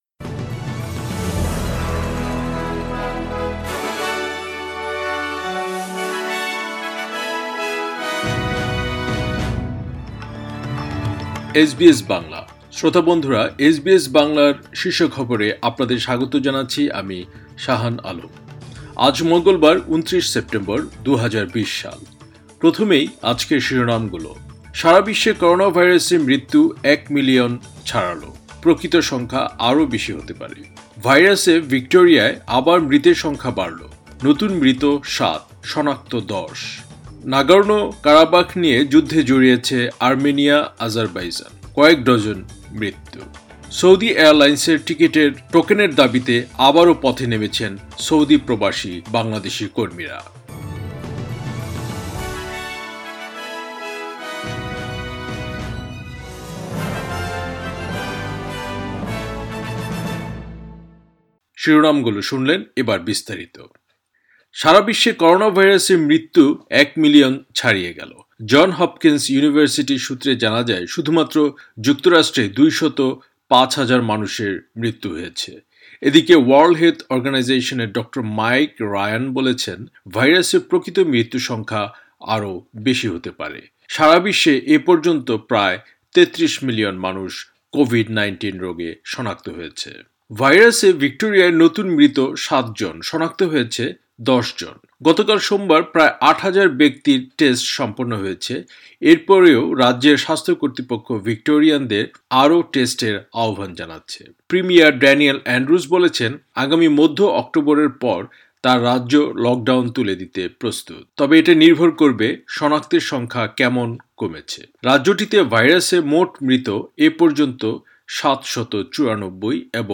এসবিএস বাংলা শীর্ষ খবর, ২৯ সেপ্টেম্বর, ২০২০